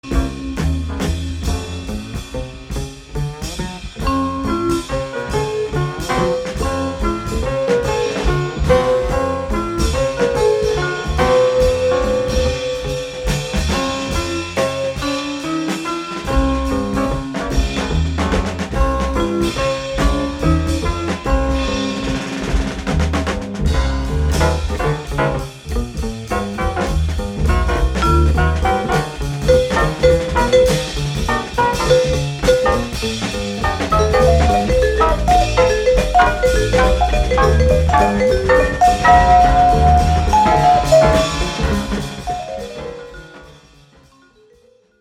life-affirming